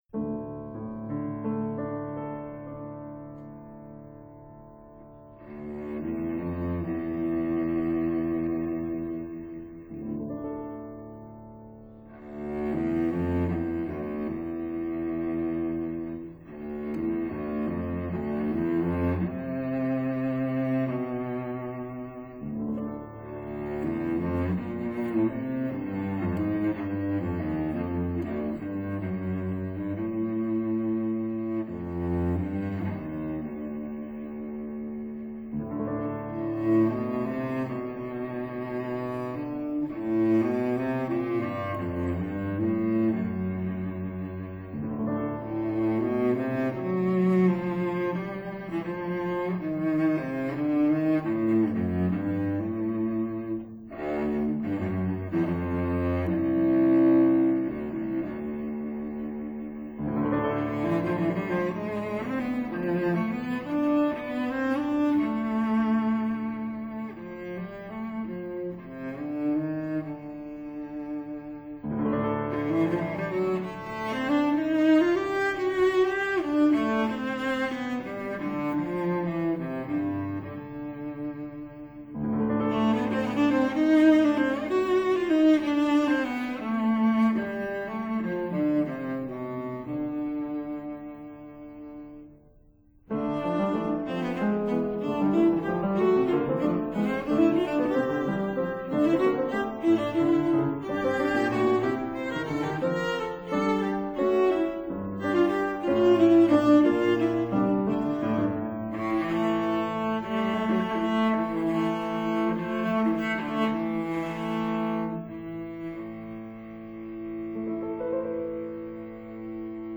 violin
cello
bassoon
piano